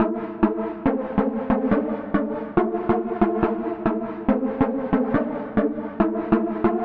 描述：欢快的舞蹈主角
Tag: 140 bpm Trance Loops Synth Loops 1.15 MB wav Key : Unknown